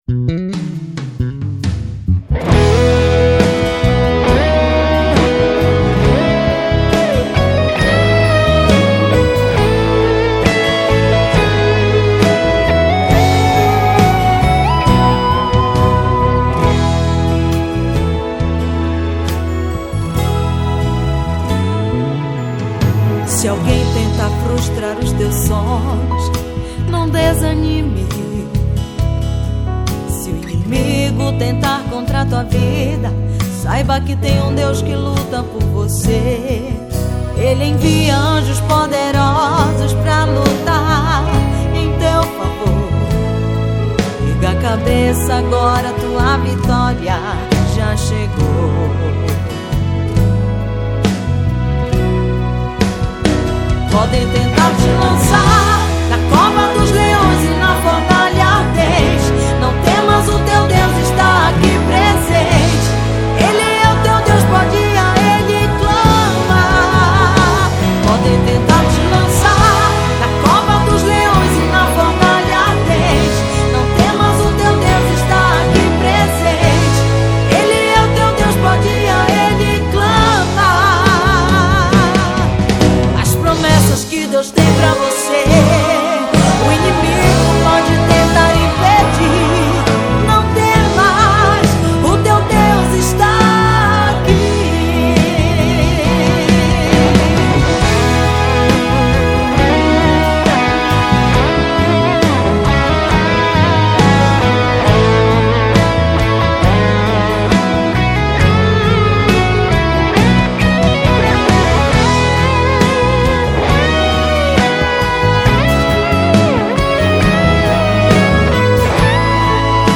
EstiloGospel